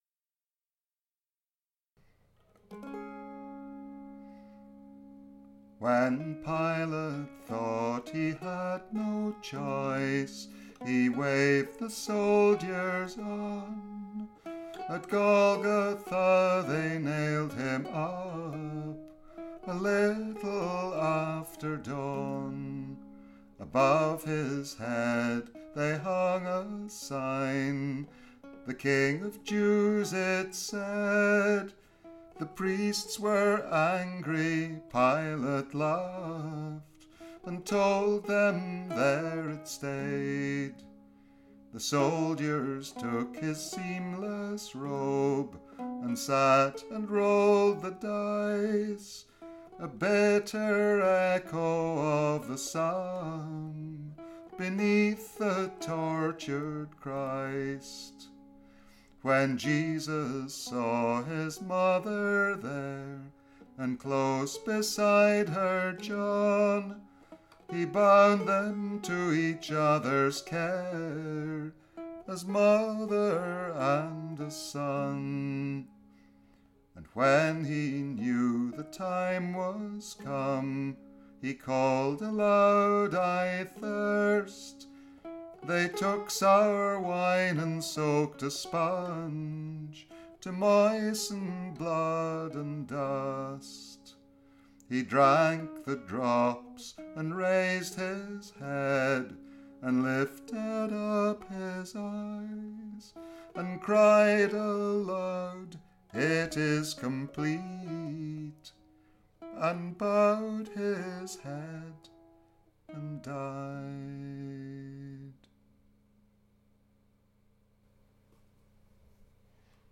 This is based on John's version. The tune is "The Leaves of Life", a traditional tune to an eerie ballad, describing a vision where the singer meets Mary at the cross.